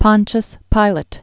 (pŏnchəs pīlət)